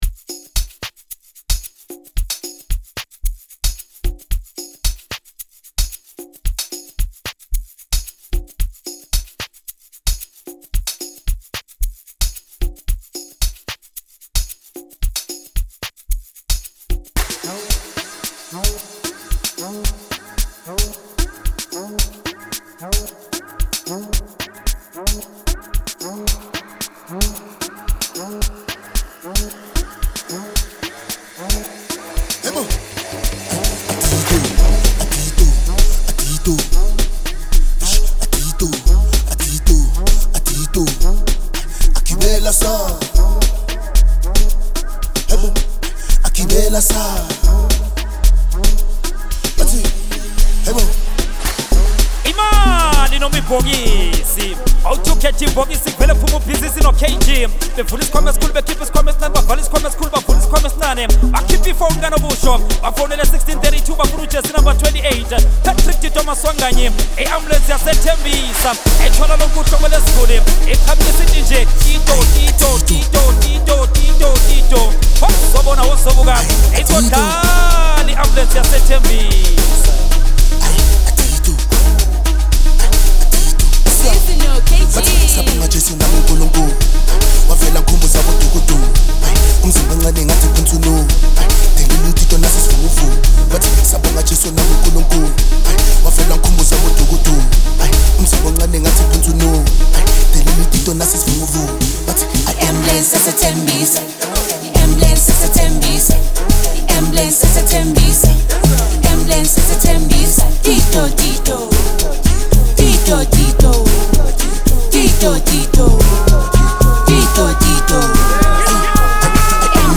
2024 Categoria: Amapiano Ouvir no Spotify Download